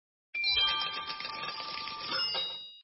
minislot_coin_fly.mp3